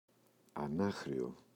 ανάχρειο, το [a’naxrʝo] – ΔΠΗ